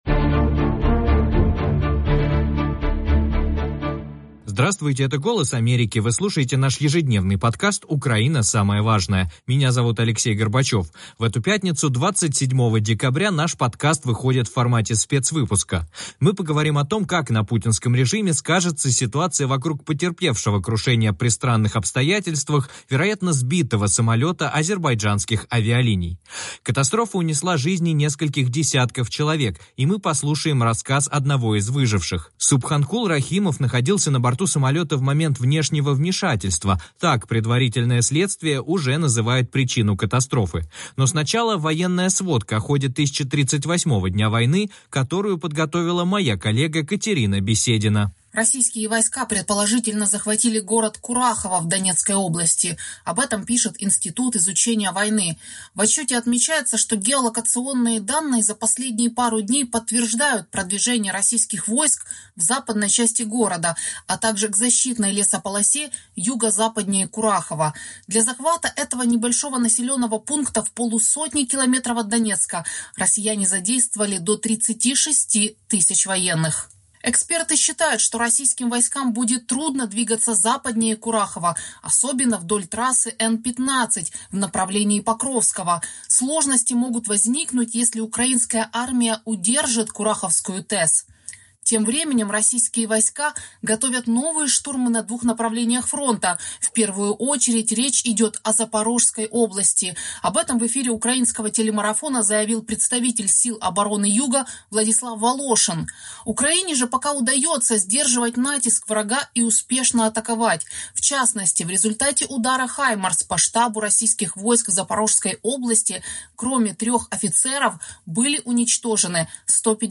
Также в выпуске – свидетельство выжившего пассажира самолета.